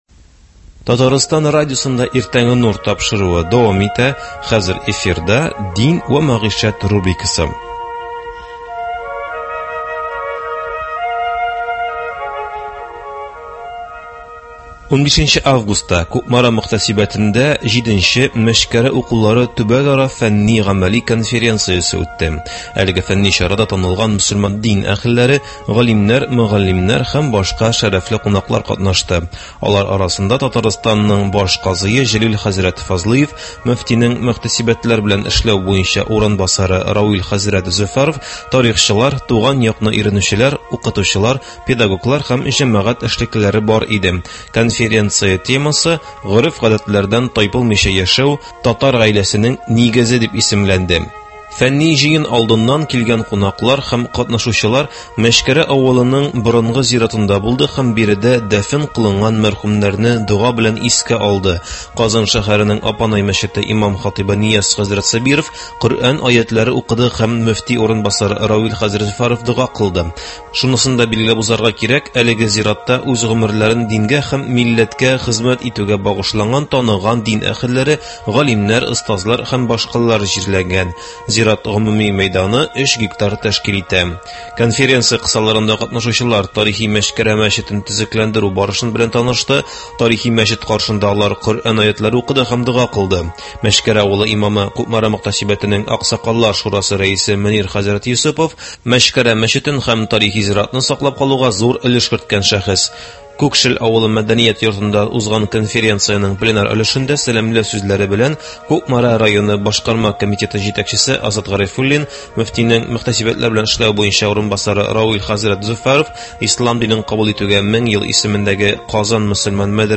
бу курслар турында әңгәмә кордык